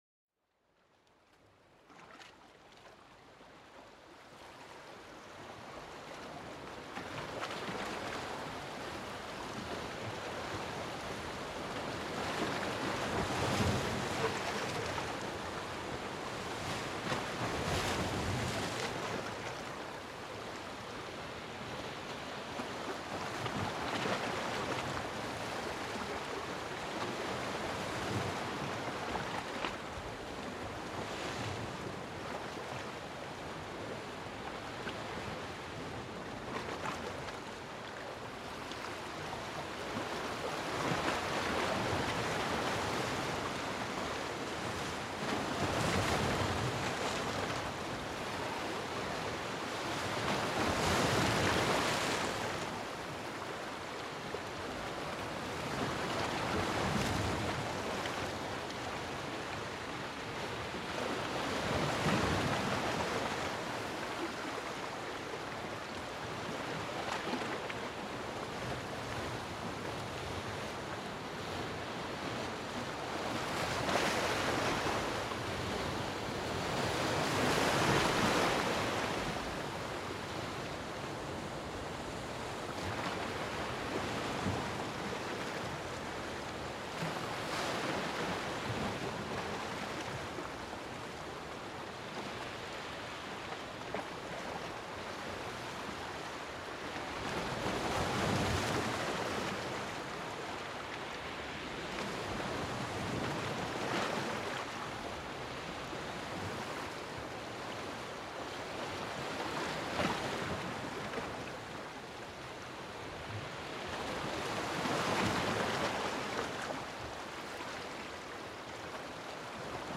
Relájate con el sonido relajante de las olas para una profunda relajación
Escucha estas suaves olas que llegan a la orilla, llevándote a un estado de calma. Cada ola aporta una sensación de tranquilidad, perfecta para liberar la tensión del día.